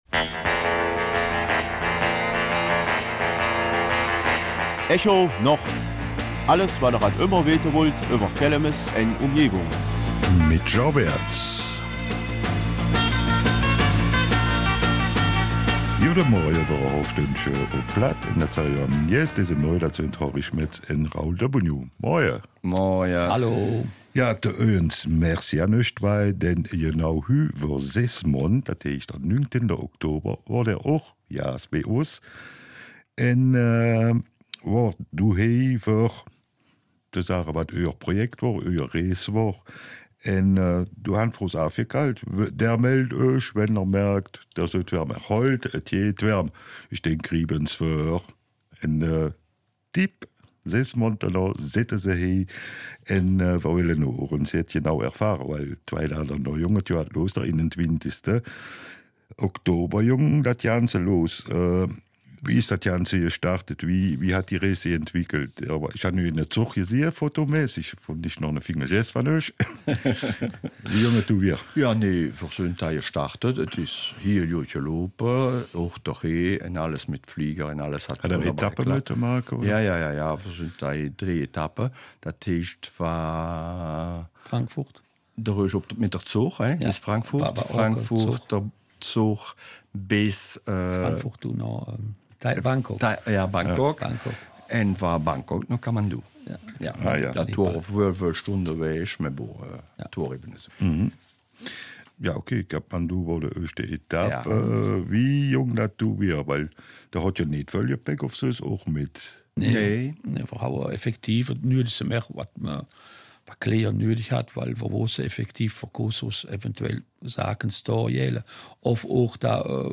Kelmiser Mundart: Erfahrungen und Ergebnis von und mit Shakti Nepal